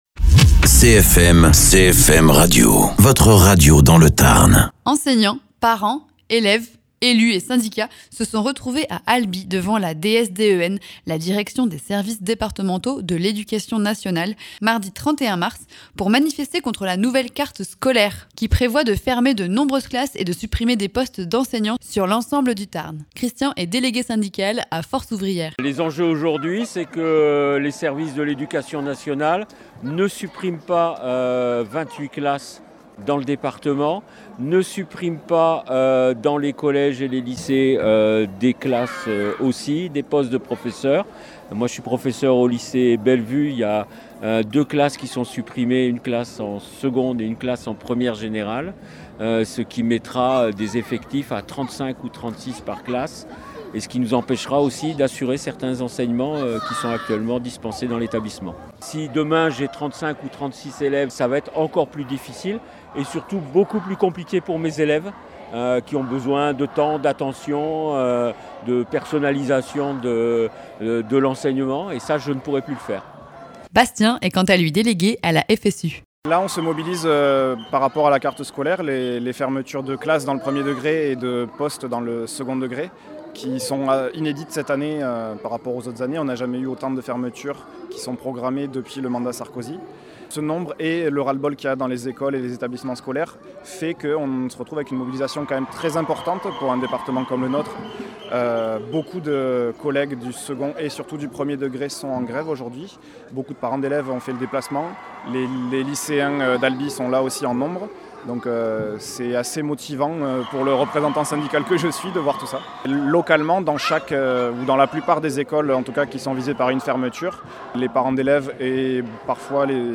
Interviews
Mardi dernier à Albi, parents d’élèves, enseignants, syndicats et élèves se sont rassemblés devant la Direction des services départementaux de l’Éducation nationale pour dénoncer la réforme de la carte scolaire qui prévoit la fermeture de 28 classes dans le Tarn à la rentrée prochaine. Dans le cortège, les inquiétudes et la colère se font entendre face aux conséquences annoncées sur les conditions d’apprentissage et l’avenir des écoles du territoire. Reportage au cœur de la mobilisation, avec les témoignages recueillis au micro de CFM Radio.
Invité(s) : Syndicats, parents d’élèves, instituteurs, élèves.